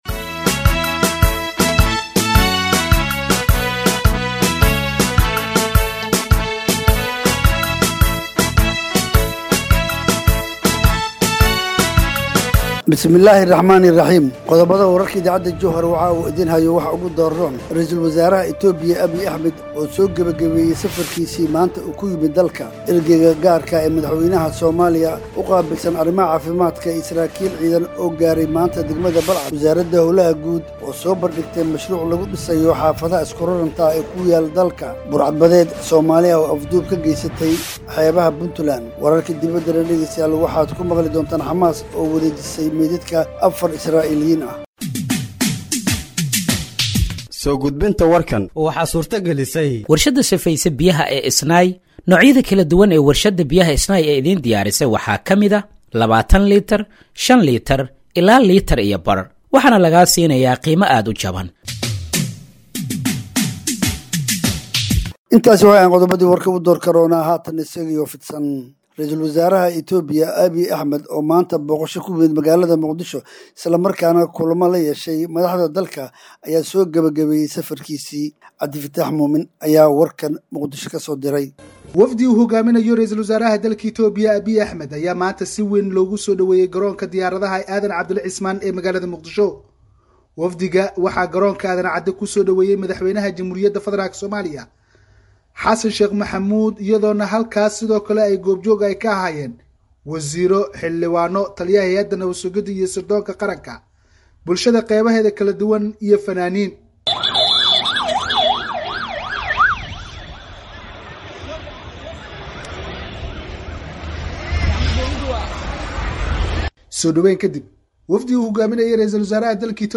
Dhageeyso Warka Habeenimo ee Radiojowhar 27/02/2025